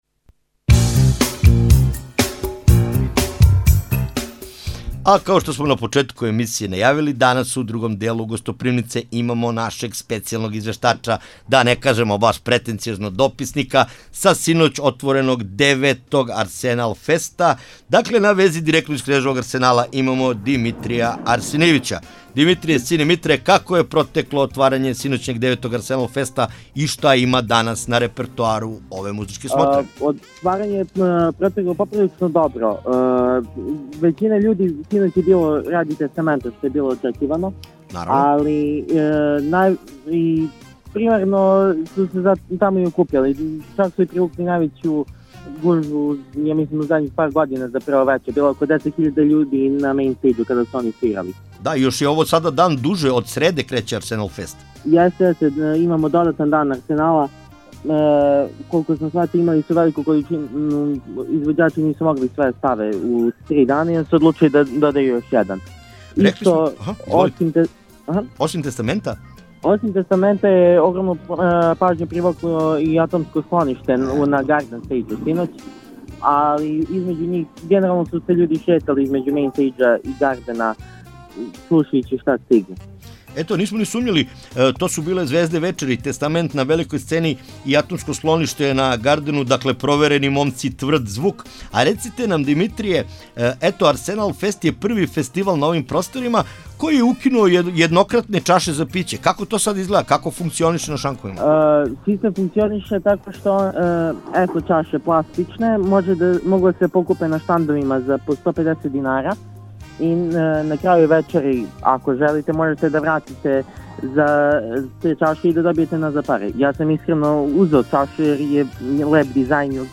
ГОСТОПРИМНИЦА – Извештај са отварања 9. Арсенал феста